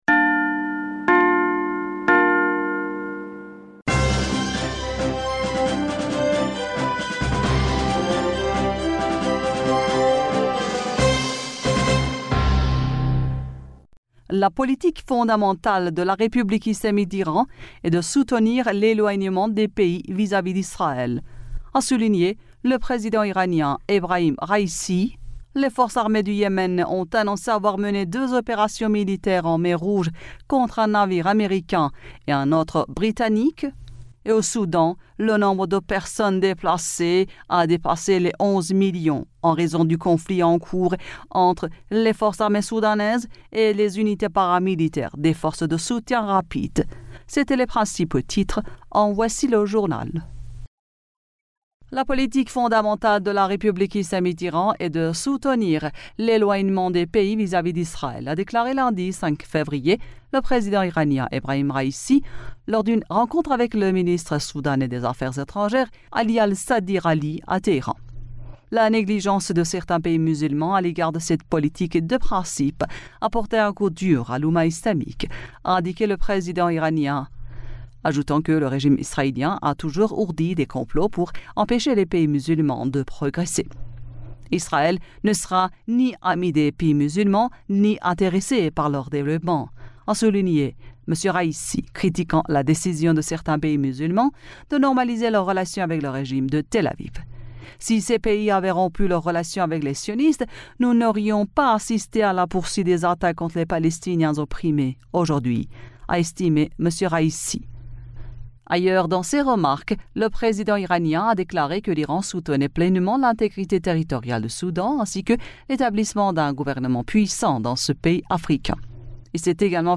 Bulletin d'information du 06 Fevrier 2024